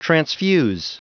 Prononciation du mot transfuse en anglais (fichier audio)
transfuse.wav